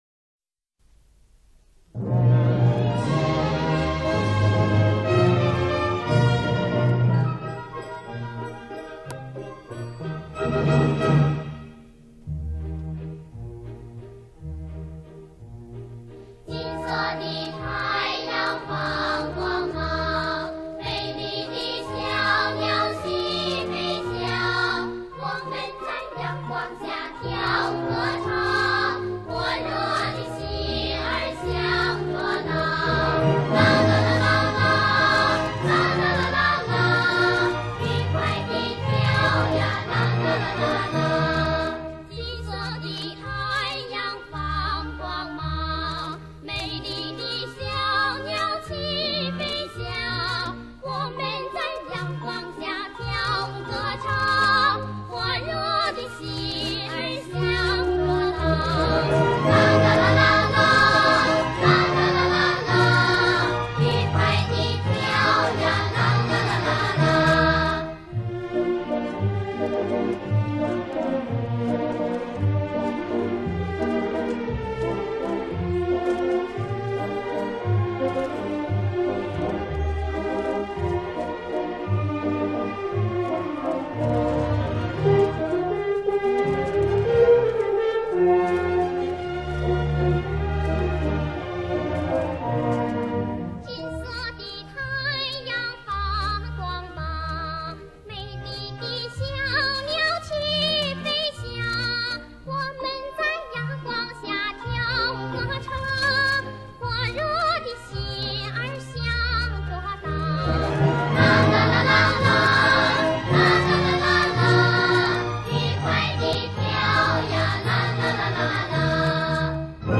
[31/5/2021]【六一歌声】：童声合唱歌曲 - 《儿童圆舞曲》